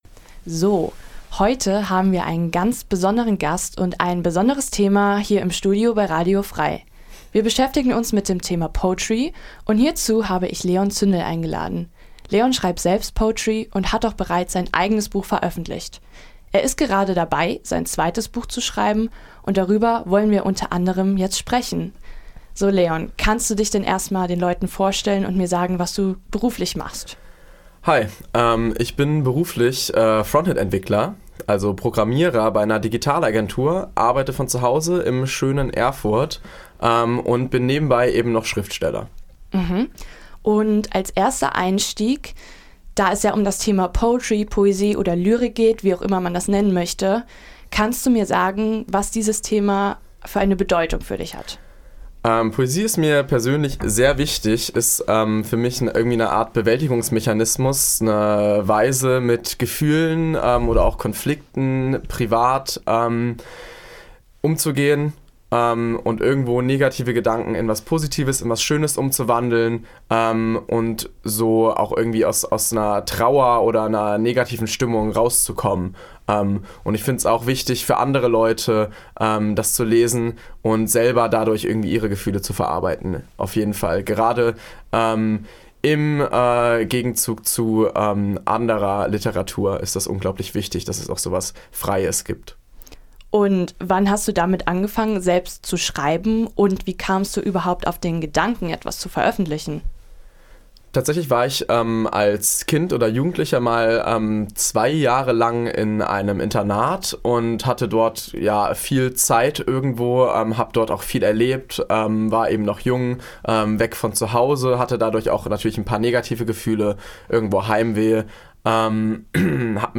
Poetry - Was ist das eigentlich? | Ein Interview